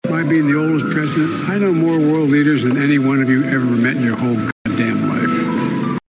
Joe Biden snapped at a reporter that asked a question about his age.
biden-snapping-at-reporter-over-his-age.mp3